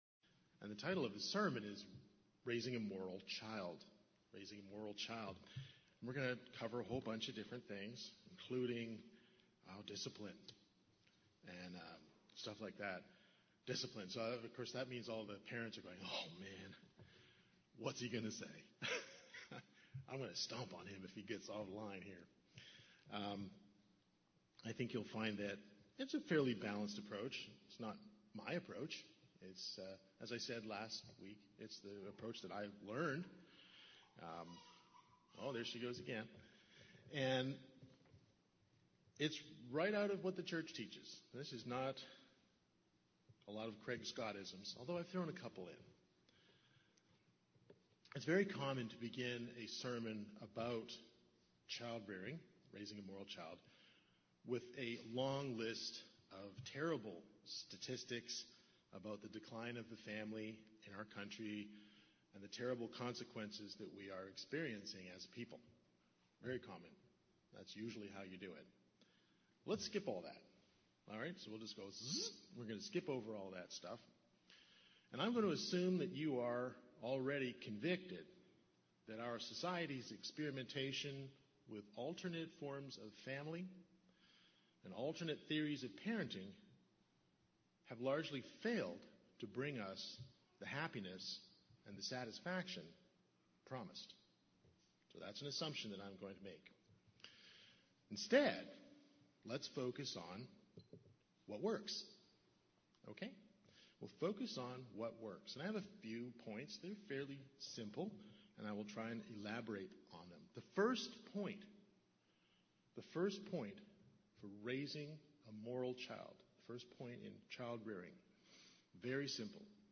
Its common to begin a sermon about children and the family with a long list of terrible statistics about the decline of the family in the country and the terrible consequences we are experiencing.